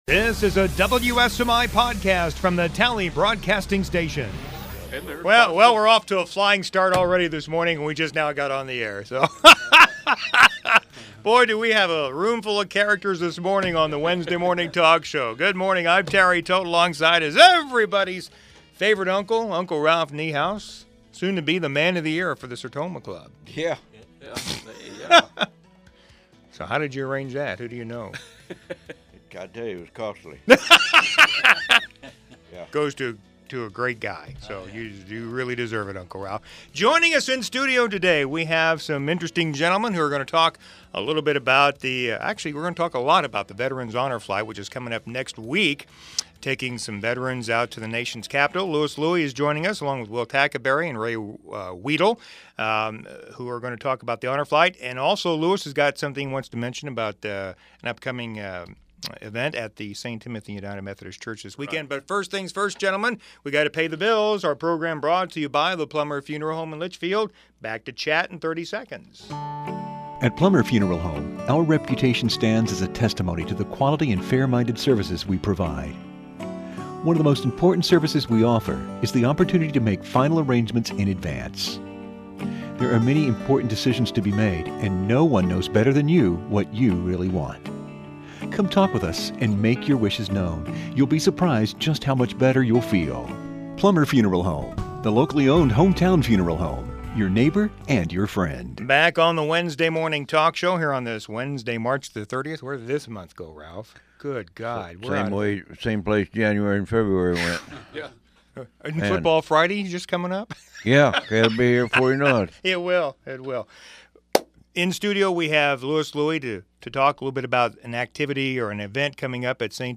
The Wednesday Morning Talk Show